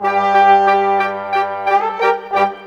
Rock-Pop 07 Brass _ Winds 02.wav